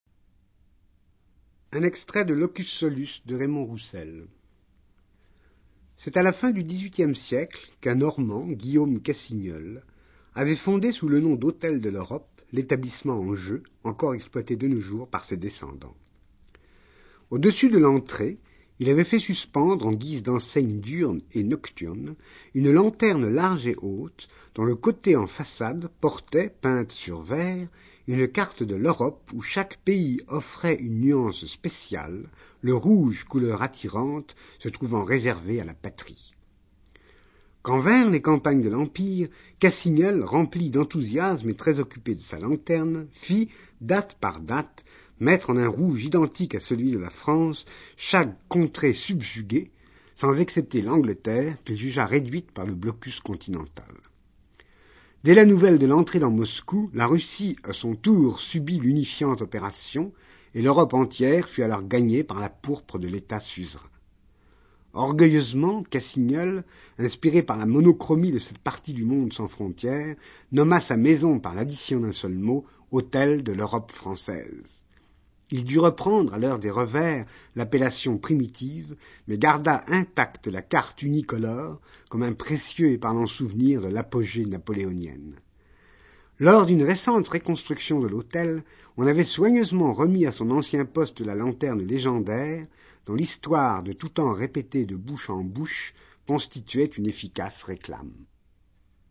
extrait lu par Perec :